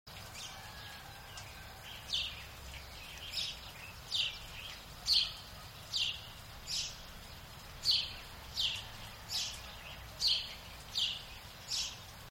Gorrión común (Passer domesticus)
Gorrión común macho – Passer domesticus
Canto